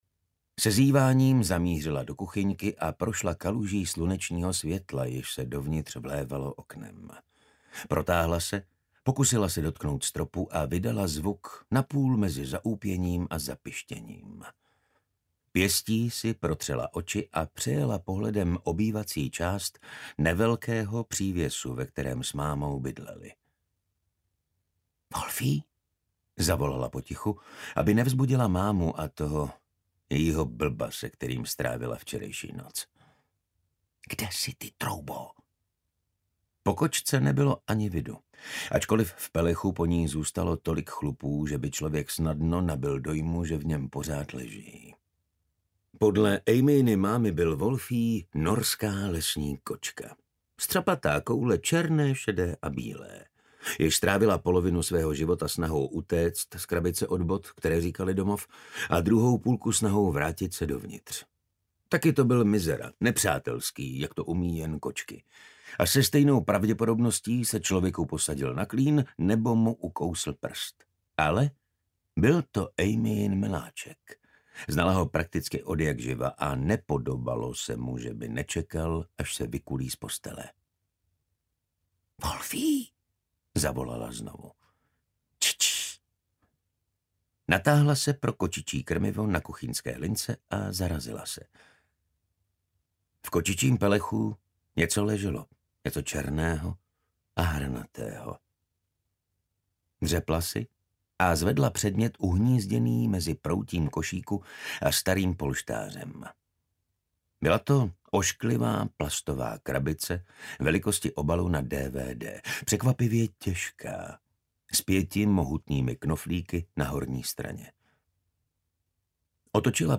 Smrtící kolotoč audiokniha
Ukázka z knihy